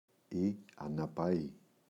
αναπαή, η [anapa’i] – ΔΠΗ